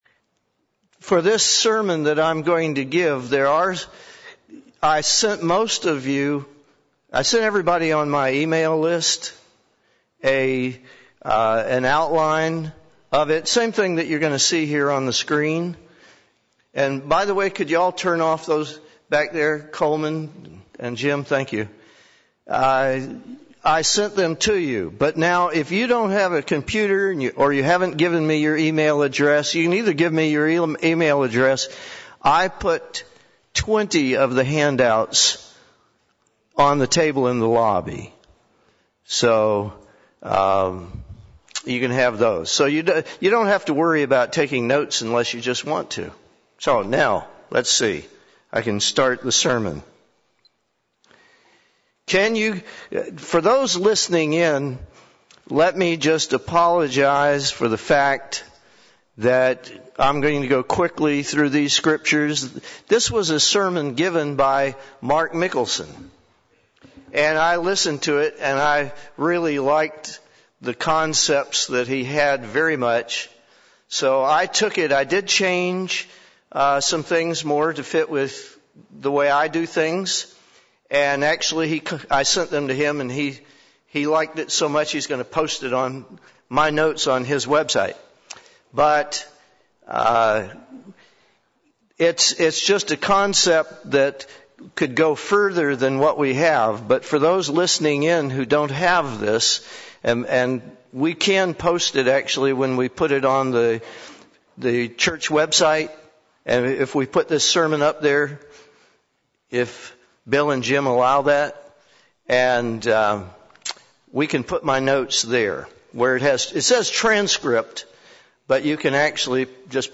UCG Sermon Notes THE ONENESS OF GOD IT IS THE FATHER WHO IS THE ARCHITECT BUT IT IS THE SON WHO IS THE BUILDER John 10:30 I and My Father are one.